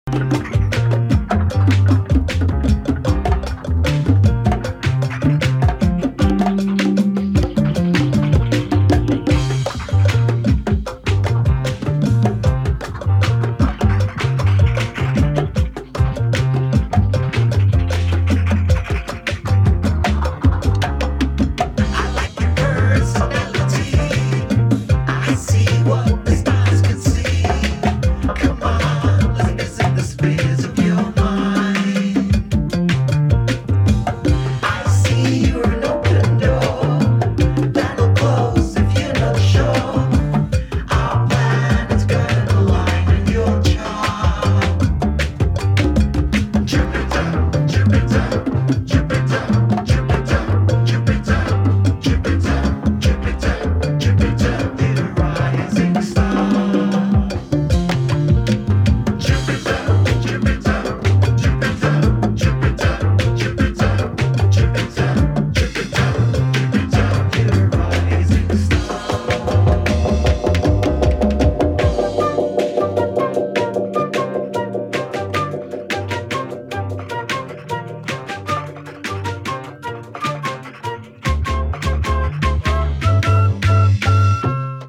スリリングなアフロビート、リラクシンなジャズファンク、フリージャズ、マンボ